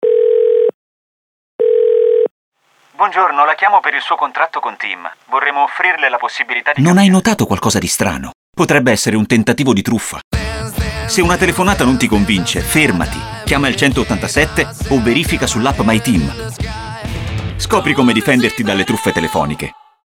Ecco l’audio della campagna radio della campagna di informazione per contrastare il fenomeno delle truffe telefoniche:
TIM_Radio-campagna-contro-le-trufffe-telefoniche_luglio-2024.mp3